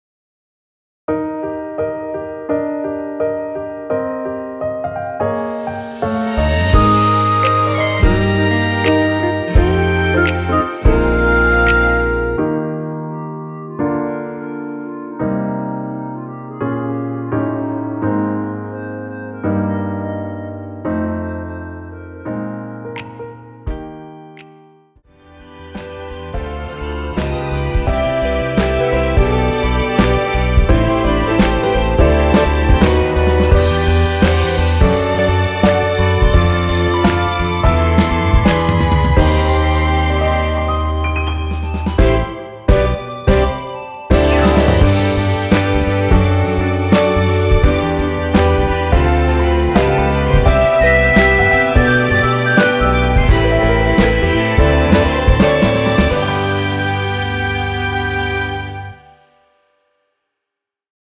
엔딩이 페이드 아웃이라 노래 하기 좋게 엔딩을 만들어 놓았습니다.(미리듣기 참조)
원키에서(+2)올린 멜로디 포함된 MR입니다.
Eb
앞부분30초, 뒷부분30초씩 편집해서 올려 드리고 있습니다.
중간에 음이 끈어지고 다시 나오는 이유는